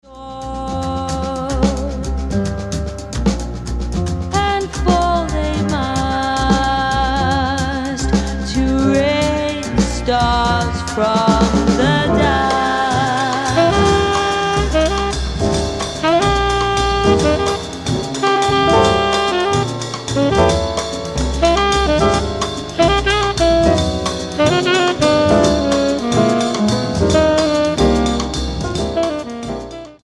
full live band